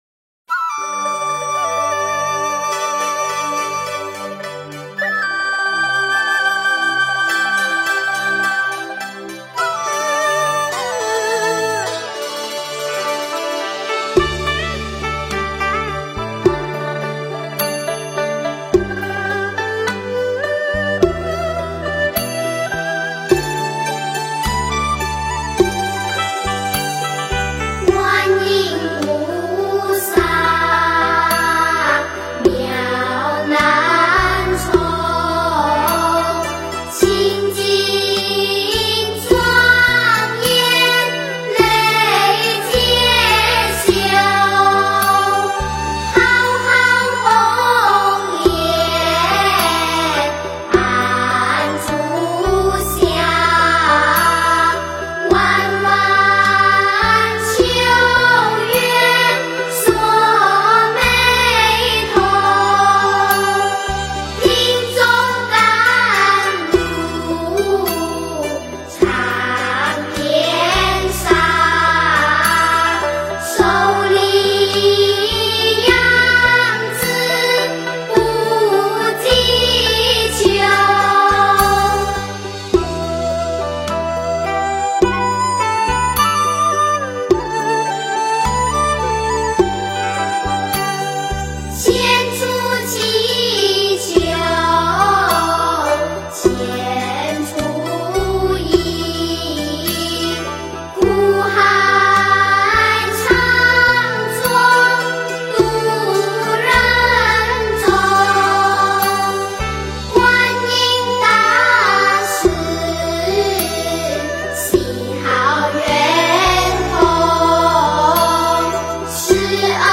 观音菩萨赞--佛教音乐